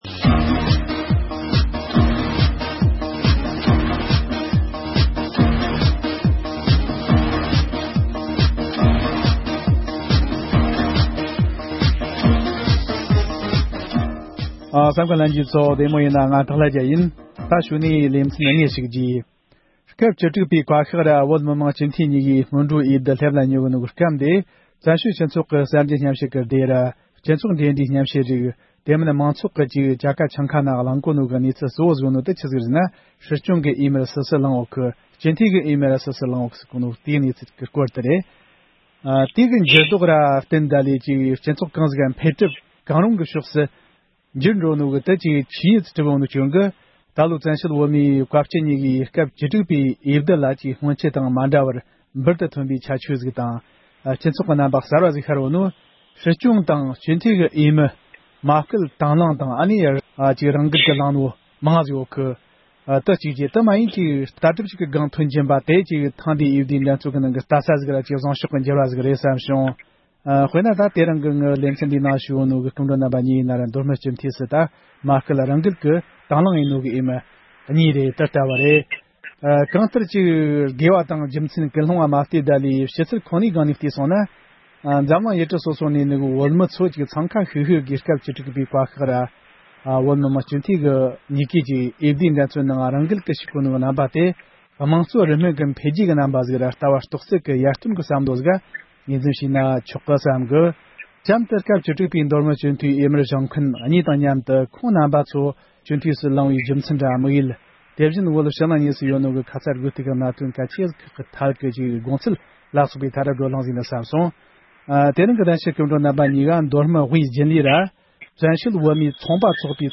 མདོ་སྨད་སྤྱི་འཐུས་འོས་མིར་གླེང་སློང་།